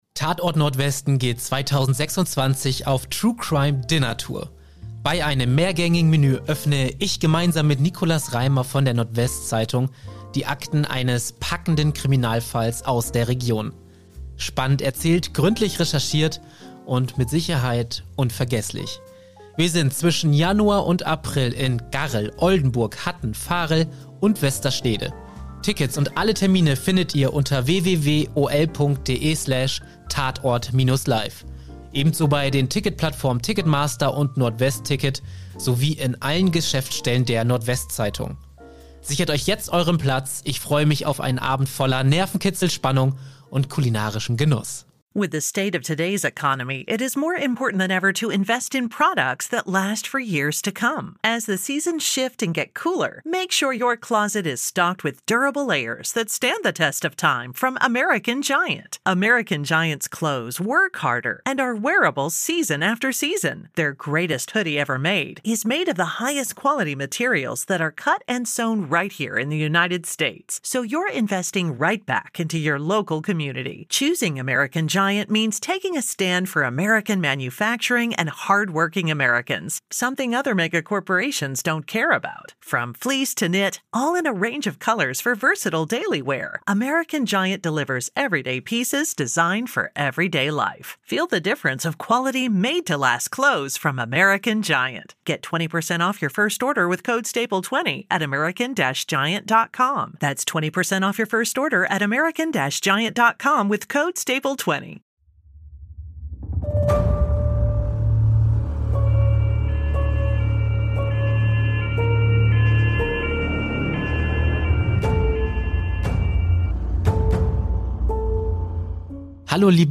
Ihr kört einen exklusiven Mitschnitt der Live-Aufnahme in Wilhelmshaven.